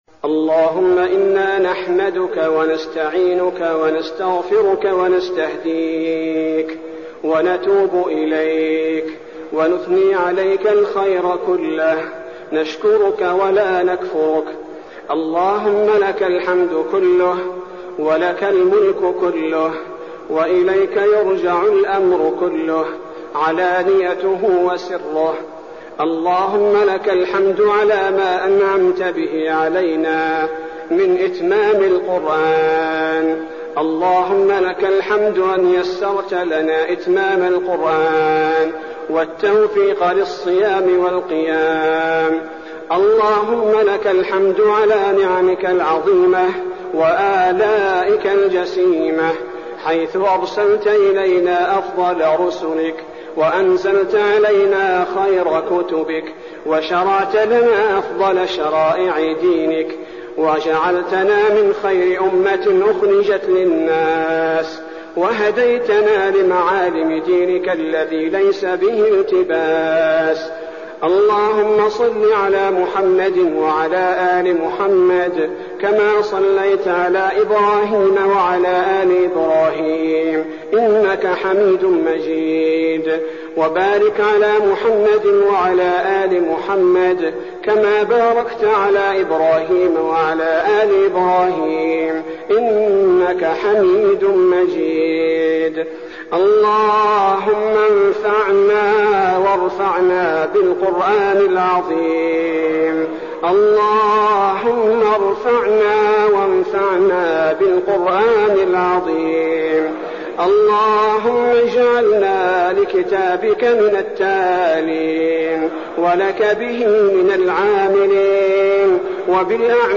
الدعاء
المكان: المسجد النبوي الشيخ: فضيلة الشيخ عبدالباري الثبيتي فضيلة الشيخ عبدالباري الثبيتي الدعاء